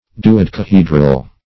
Search Result for " duodecahedral" : The Collaborative International Dictionary of English v.0.48: Duodecahedral \Du`o*dec`a*he"dral\, a., Duodecahedron \Du`o*dec`a*he"dron\, n. See Dodecahedral , and Dodecahedron .
duodecahedral.mp3